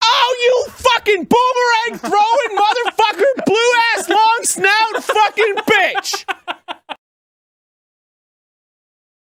Game Grumps Arin Raging In Link Faces Of Evil Sound Effect Download: Instant Soundboard Button